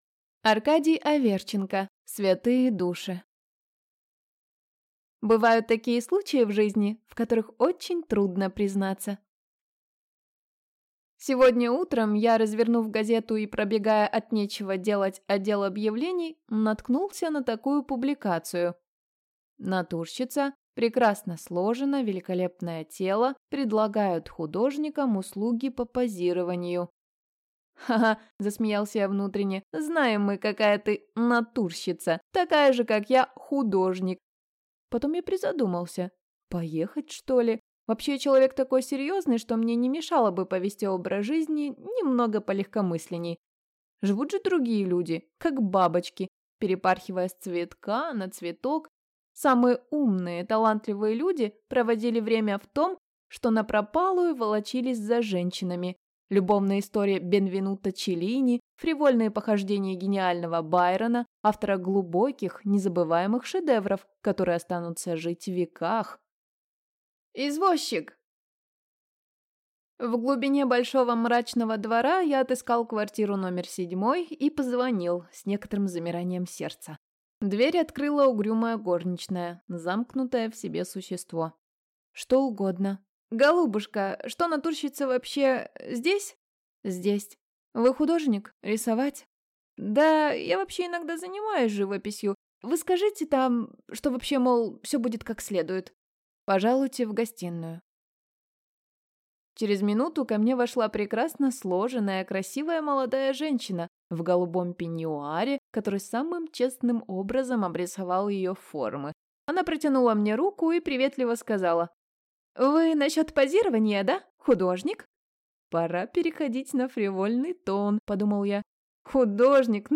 Аудиокнига Святые души | Библиотека аудиокниг